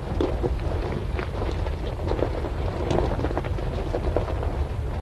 goldenpig_roll.ogg